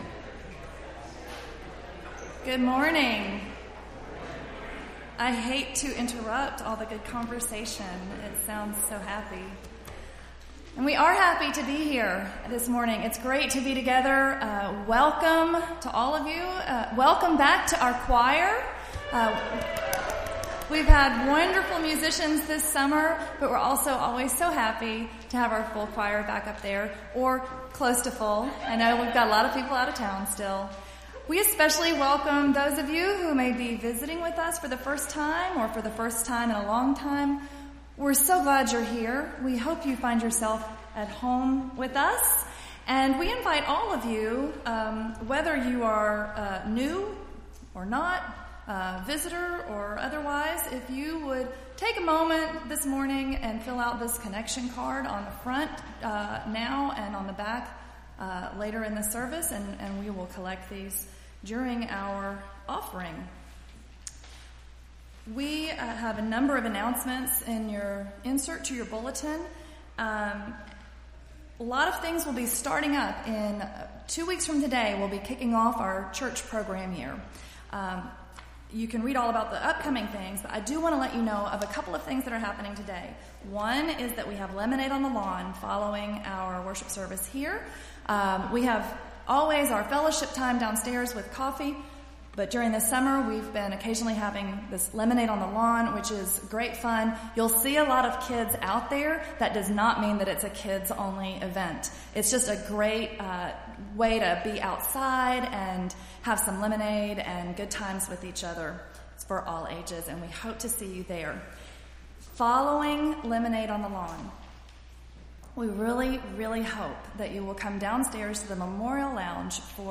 Entire August 27th Service